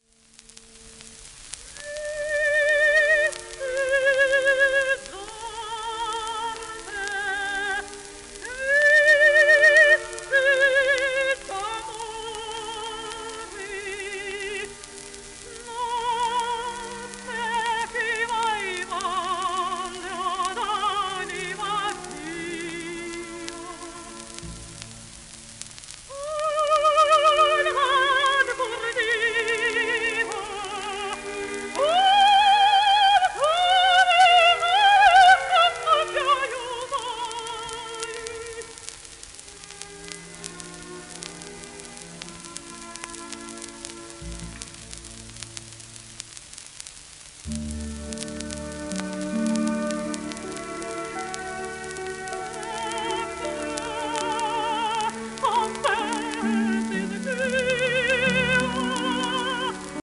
w/オーケストラ
1928年頃録音